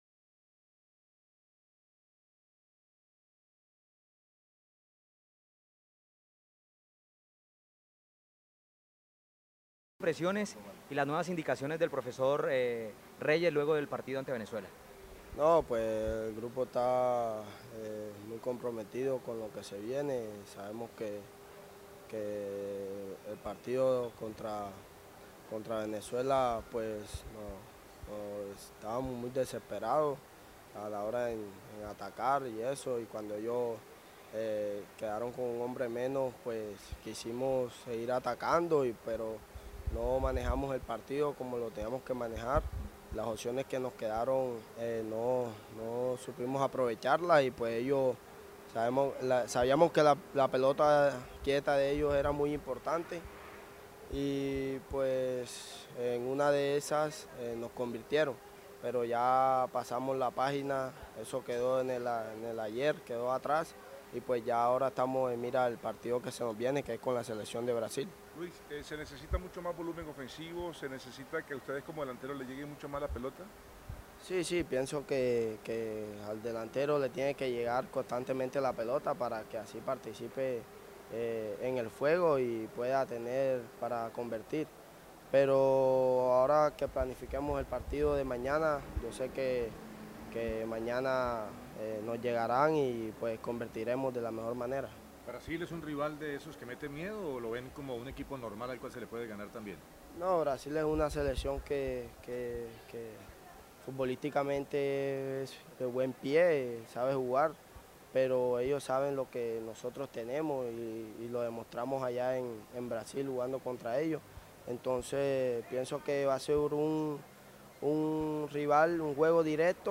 atendieron a la prensa:
(Delantero)